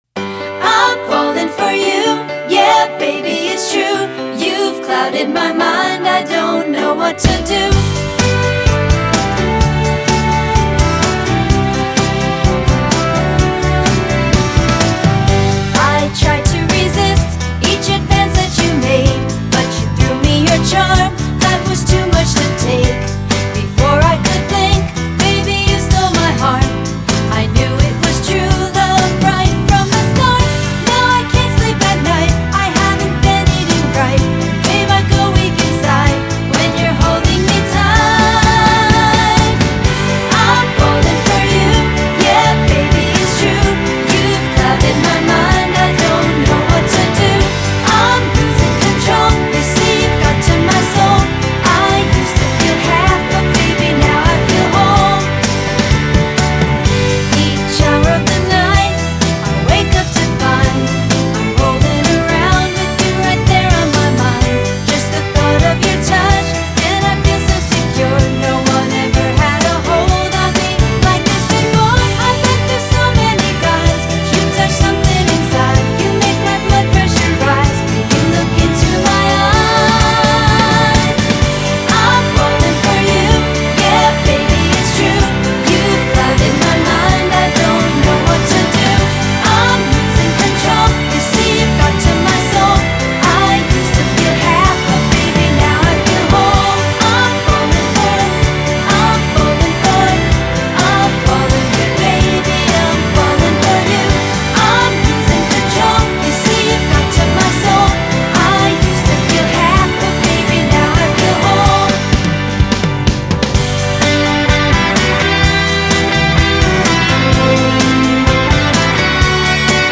"I'm Fallin' For You" (pop)